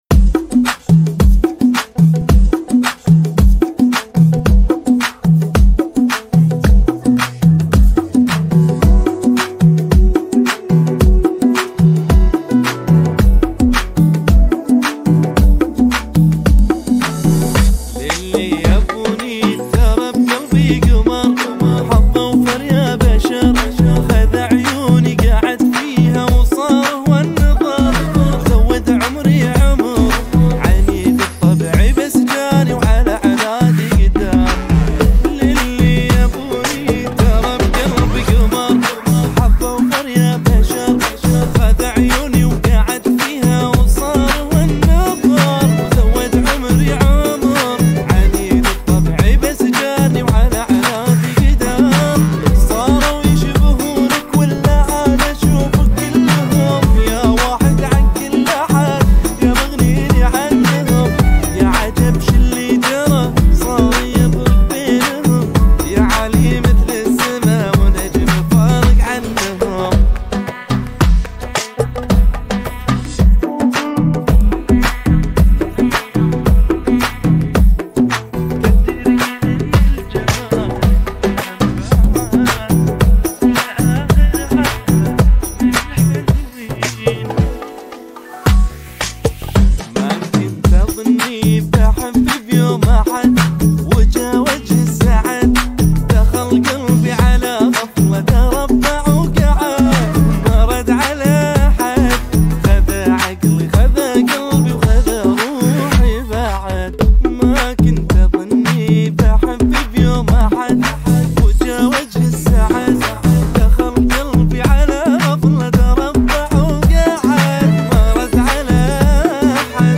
108 bpm